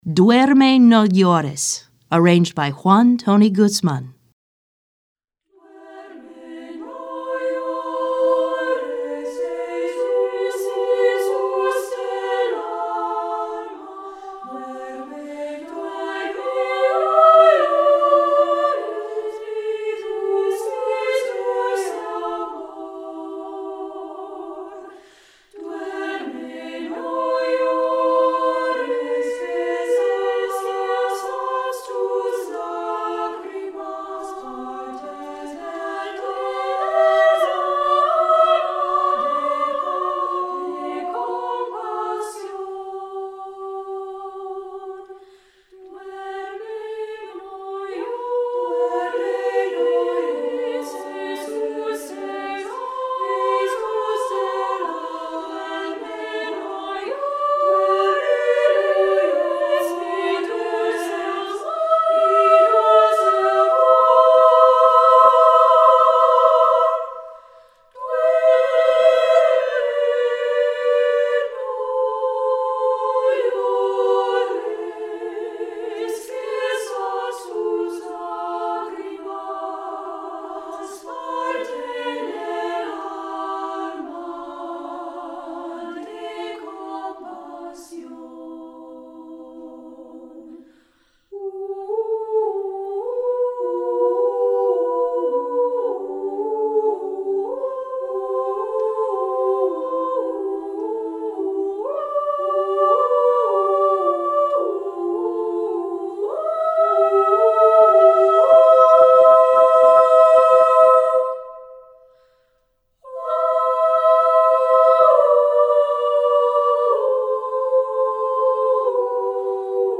This is a traditional Christmas Lullaby from Mexico.